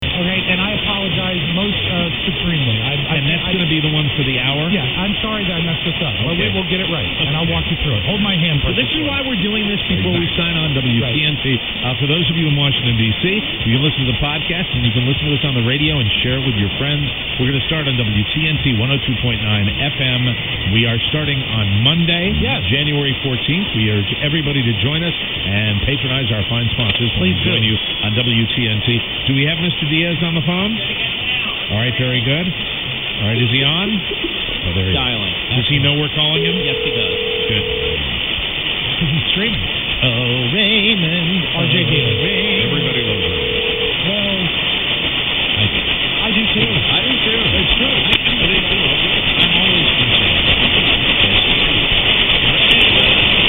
1630 WTNT 102.9 announcements via a talk show on pres WRDW.